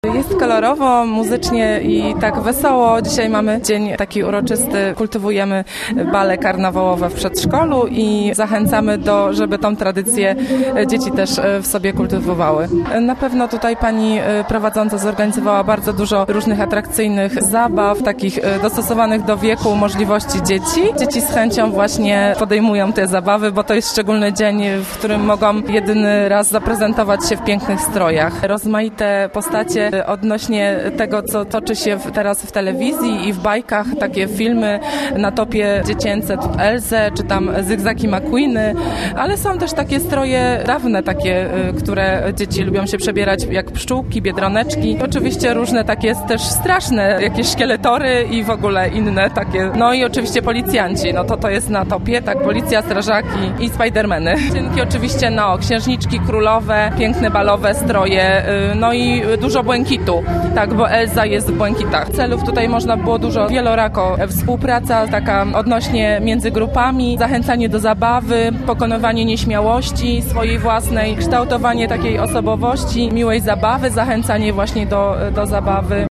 Bajkowy bal karnawałowy w Przedszkolu nr 2 w Wieluniu (zdjęcia)